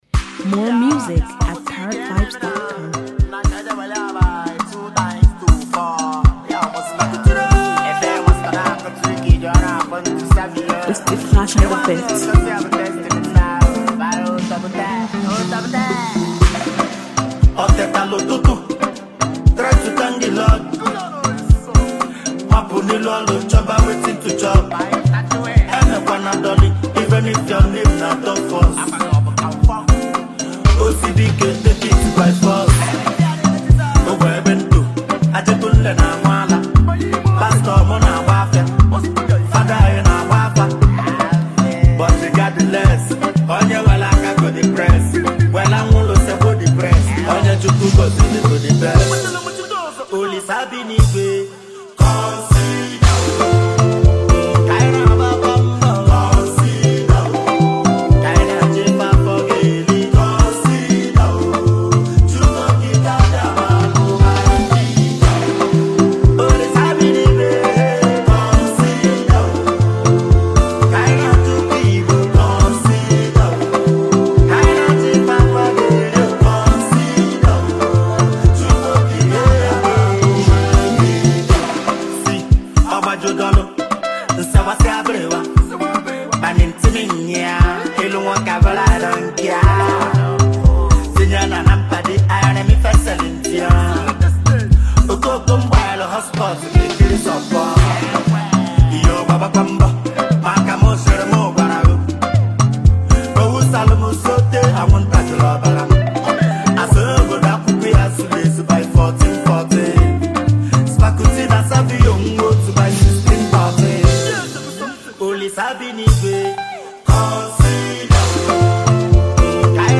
a heavyweight Nigerian indigenous rapper and wordsmith.